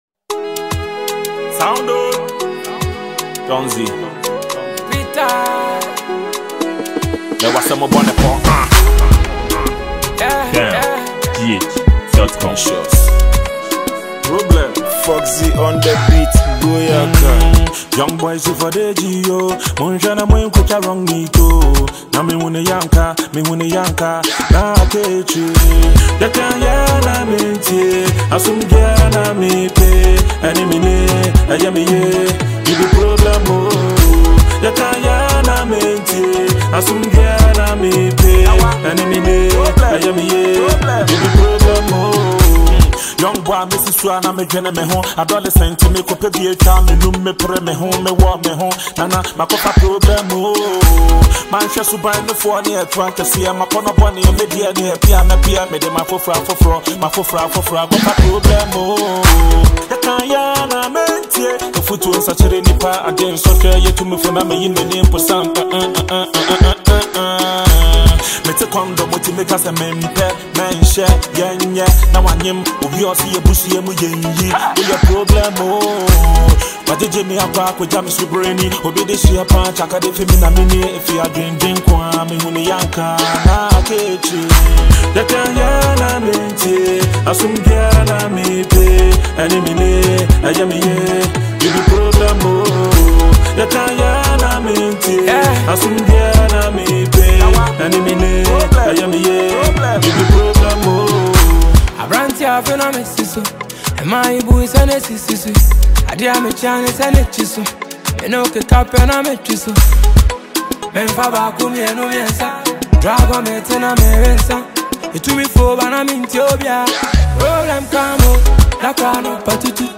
This is a Ghanaian song released in 2025.